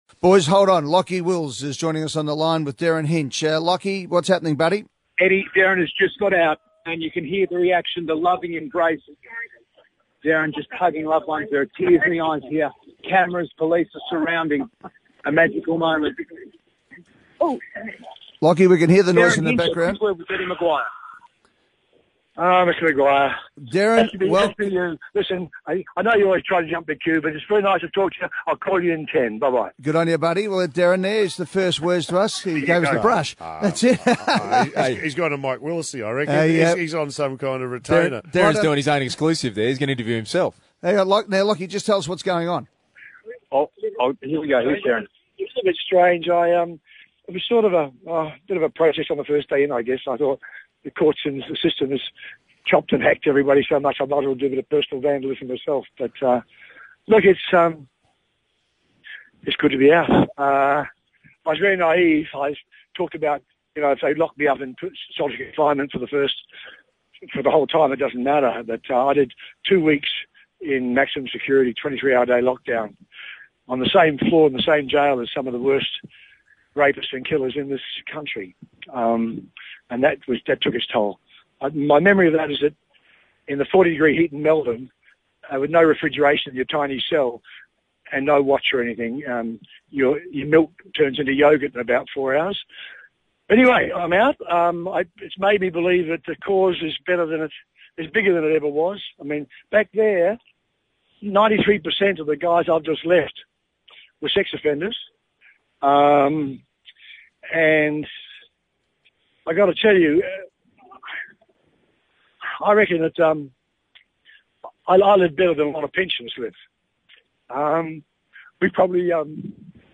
Derryn Hinch speaks immediately after his release from jail.